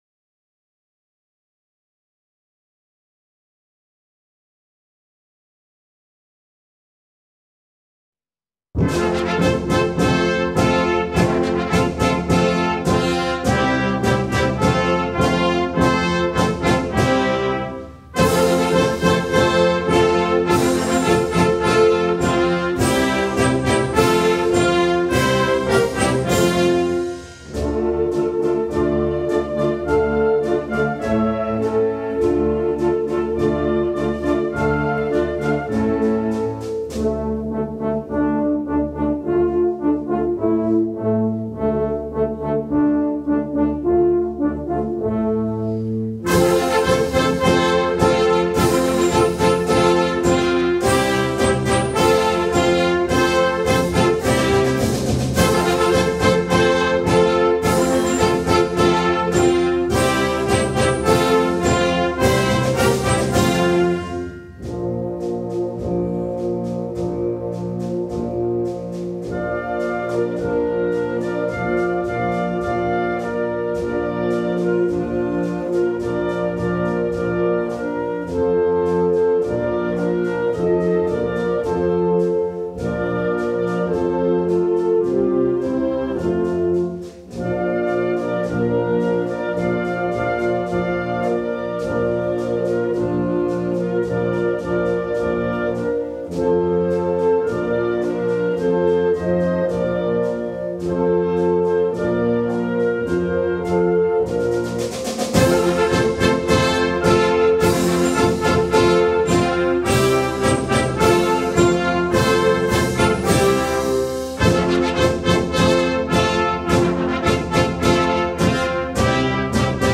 sassofono